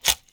Close Combat Break Bone 4.wav